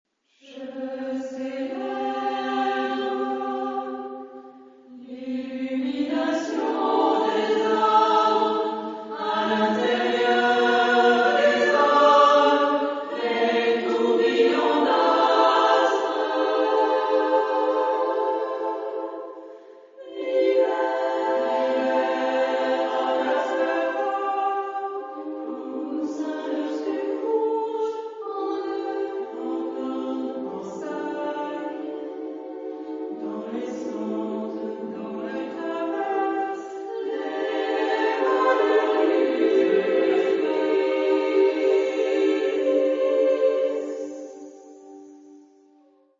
Genre-Style-Form: Secular ; Poem ; Contemporary
Mood of the piece: contrasted ; contemplative
Type of Choir: SSAA  (4 women voices )
Tonality: C major